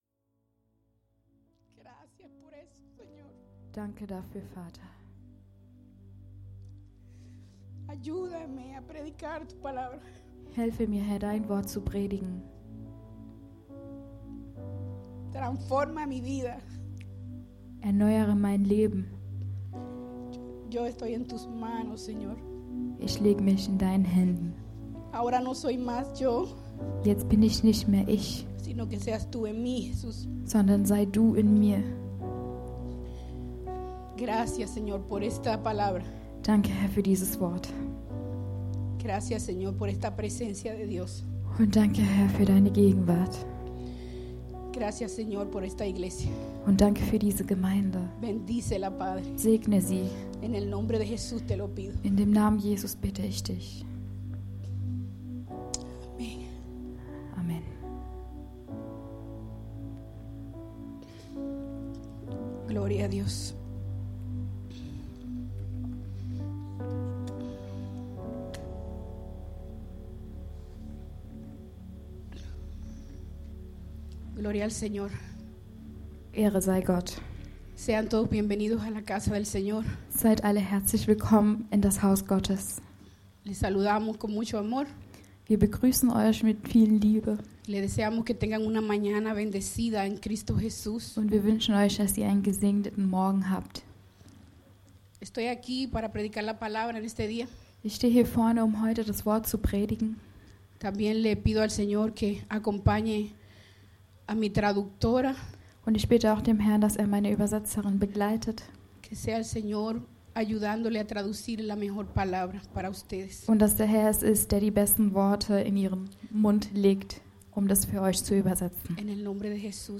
[:de]Predigten[:en]Sermons[:es]Predicaciones[:] – Seite 3 – International Church Rhein-Main